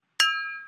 ting-mono-expected.wav